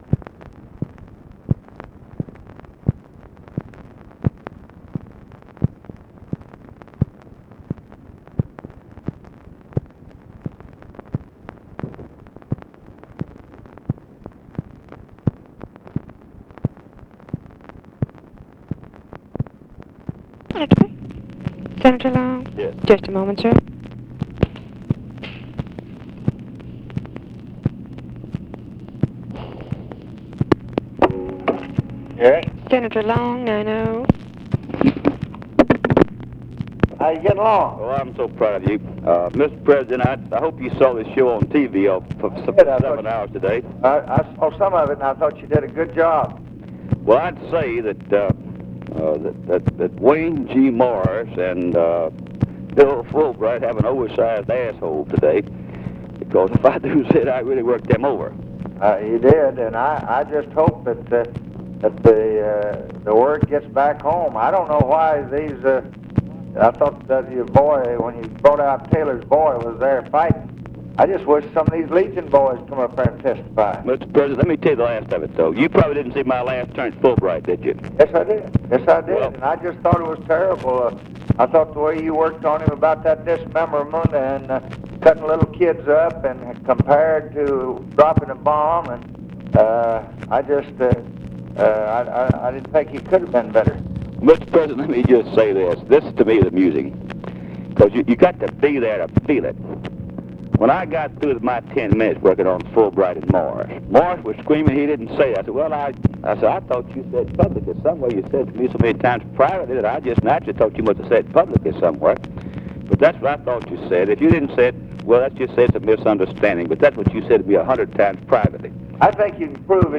Conversation with RUSSELL LONG, February 18, 1966
Secret White House Tapes